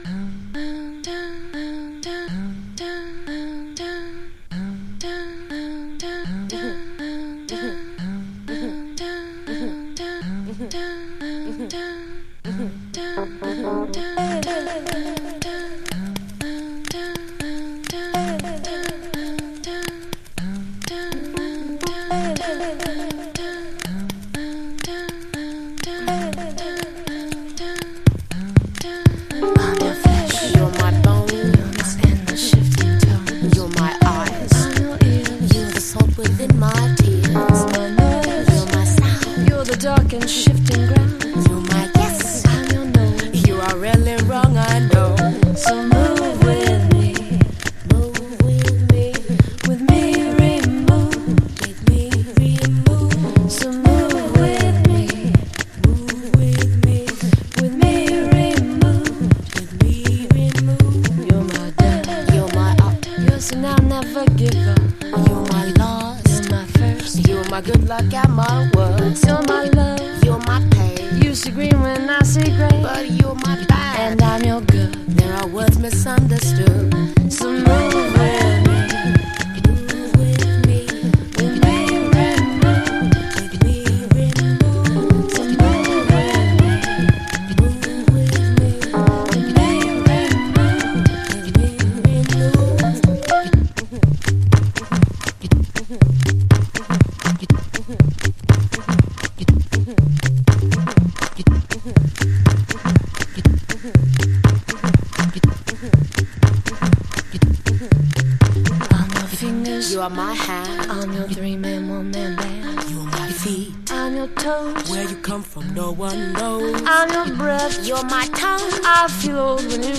House / Techno
ピアノが挿入されてからの展開に全故郷が涙した名盤。